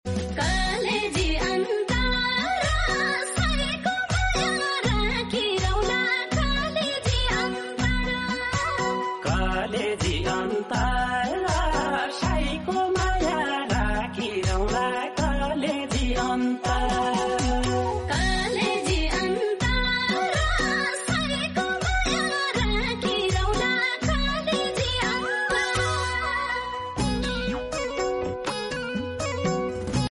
Kati Mitho Deuda Geet 😍❤ Sound Effects Free Download